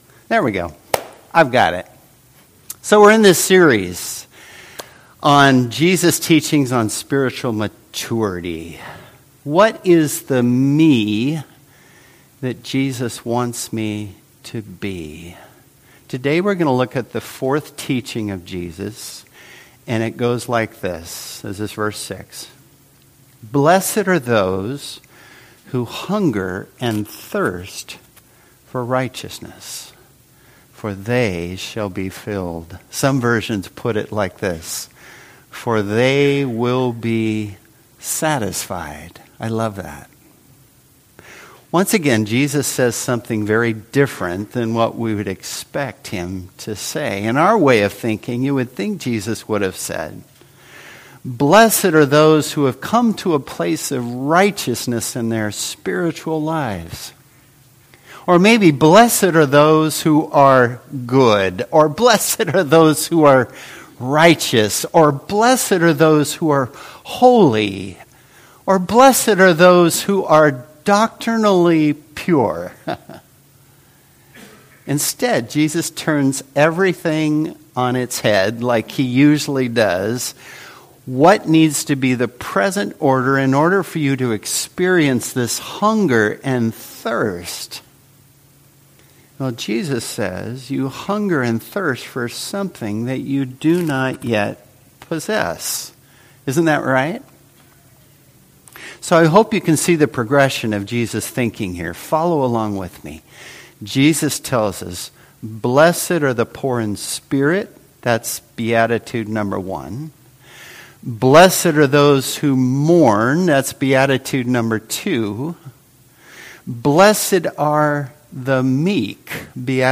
Service Type: Worship Service Topics: God's Character , Love